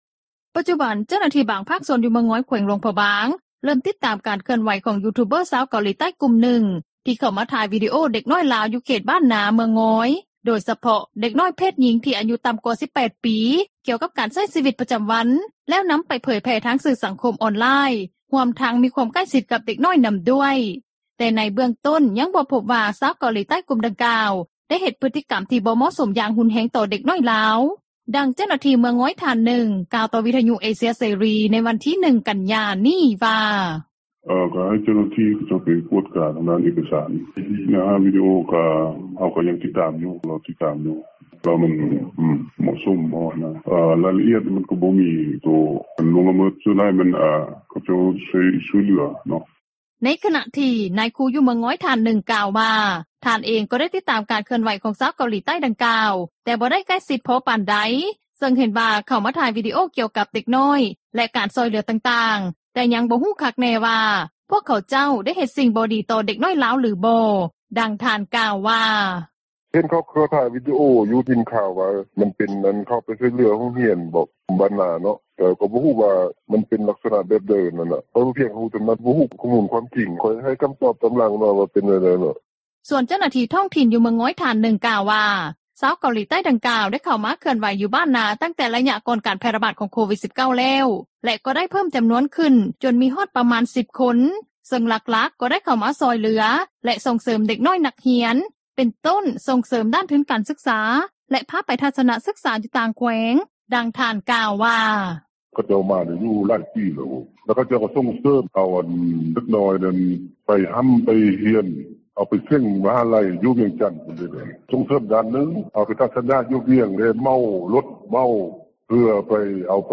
ດັ່ງເຈົ້າໜ້າທີ່ເມືອງງອຍ ທ່ານນຶ່ງ ກ່າວຕໍ່ວິທຍຸ ເອເຊັຽ ເສຣີ ໃນວັນທີ 1 ກັນຍາ ນີ້ວ່າ: